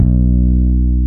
Index of /90_sSampleCDs/East Collexion - Bass S3000/Partition A/FRETLESS-E
FLS BS LOW 2.wav